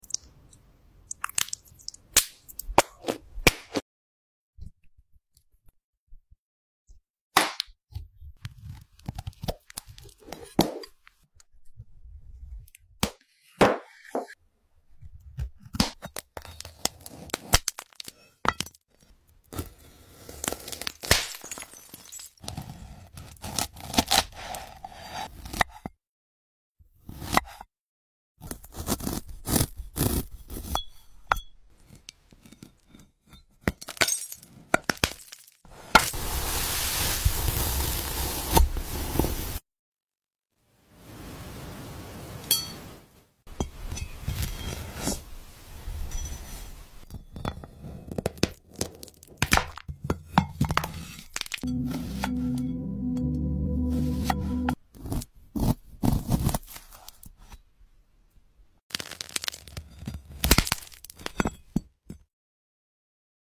eating Yummy Fruits 🍓 sound effects free download